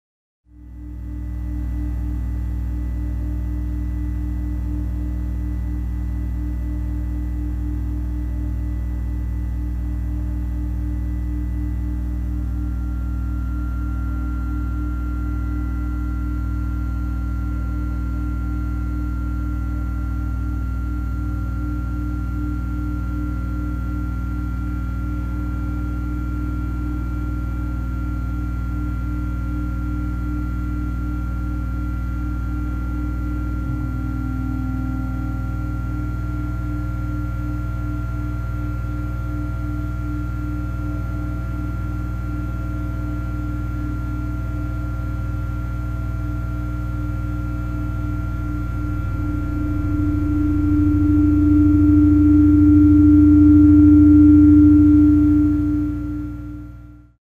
При работе эти устройства издают свой неповторимый звук, и этот пост мы посвятим звукам трансформатора — монотонному глубокому, низкочастотному жужжанию и гулу.
Звук трансформатора, в противоположность обычным электрическим разрядам, часто является низкочастотным гулом.
Низкочастотный гул трансформатора:
nizkochastotnyj-gul-transformatora.mp3